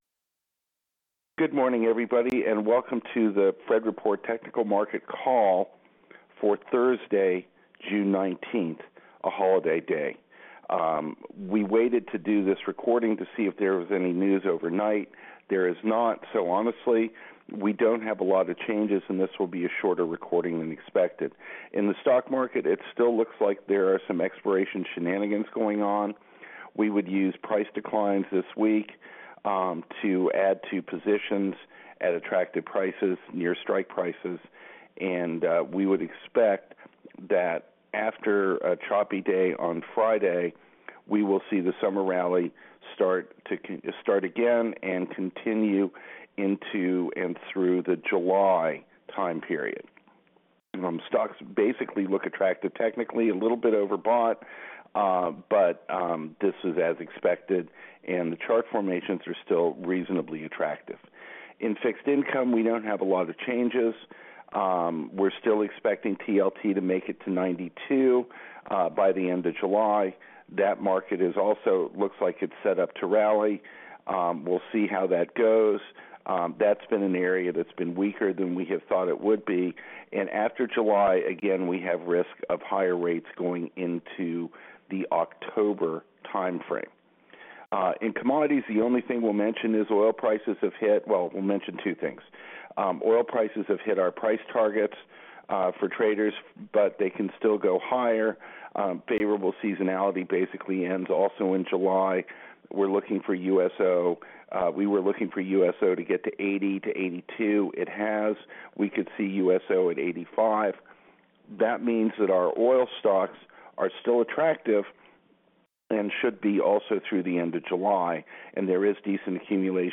Conference Call Recording: